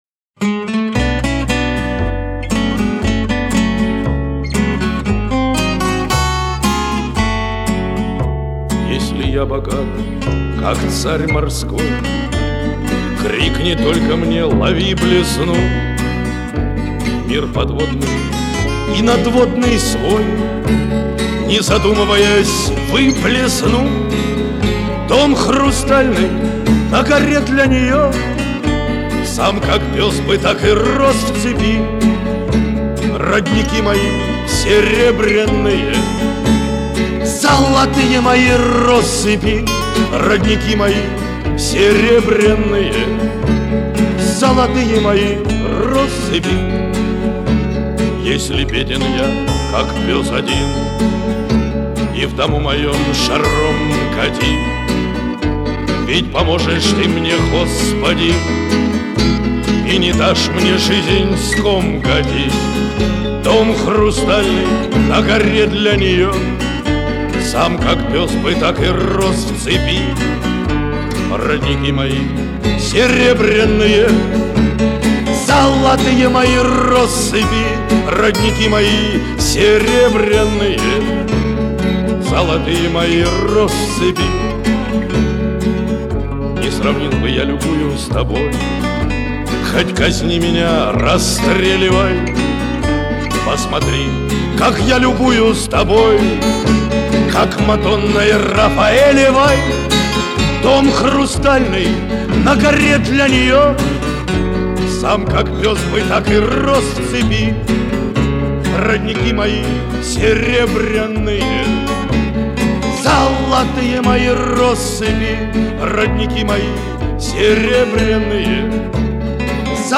Пісня у виконанні автора